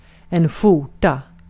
Uttal
Uttal Okänd accent: IPA: /ˈɧʊˌrta/ Ordet hittades på dessa språk: svenska Översättning 1. gömlek Artikel: en .